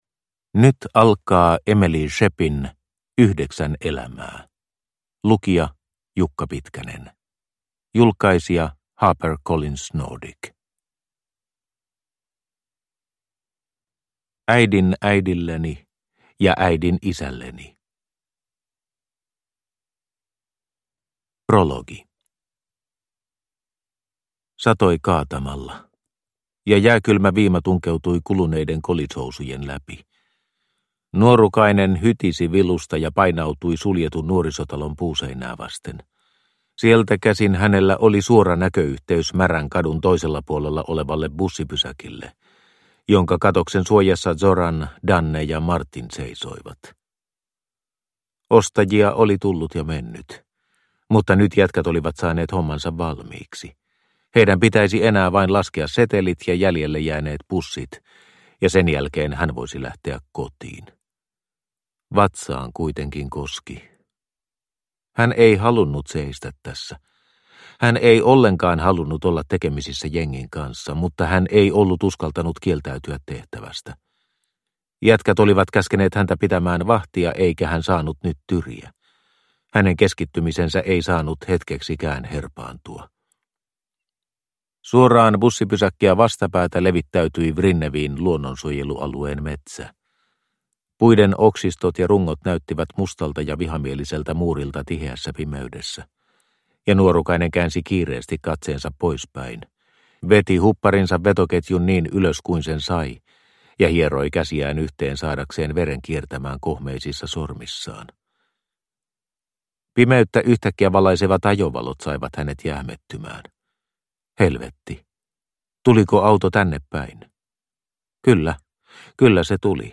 Yhdeksän elämää – Ljudbok – Laddas ner